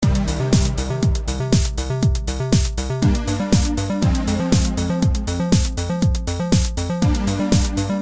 Tag: 125 bpm Techno Loops Bass Loops 661.54 KB wav Key : Unknown